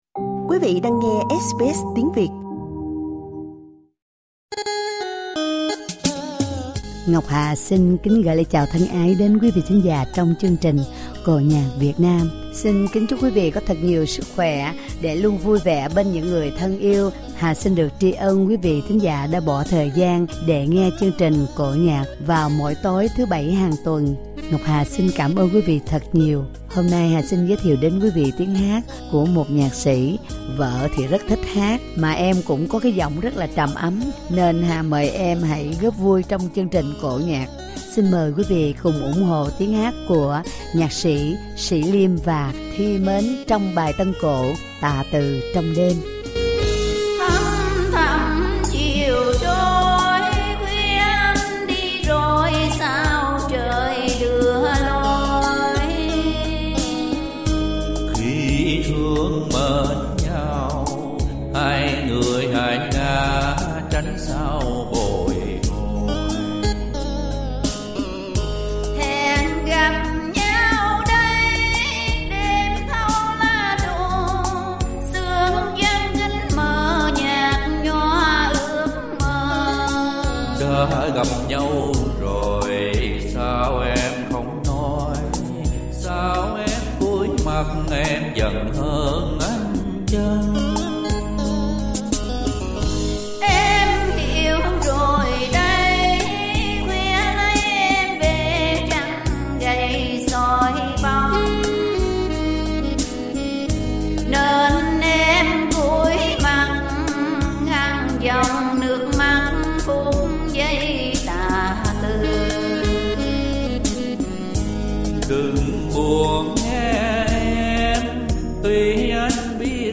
12 câu đảo ngũ cung
ca cảnh